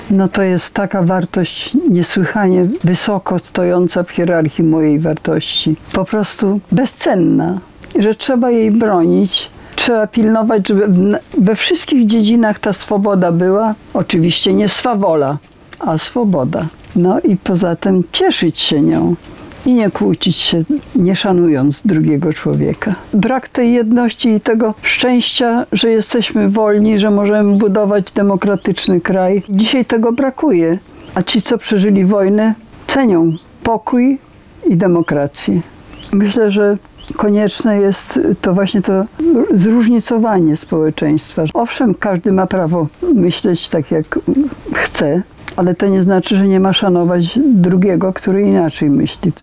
Z okazji 30. rocznicy pierwszych w powojennej historii Polski częściowo wolnych wyborów do Sejmu udzieliła Radiu 5 wywiadu, którego fragmenty zamieszczamy. https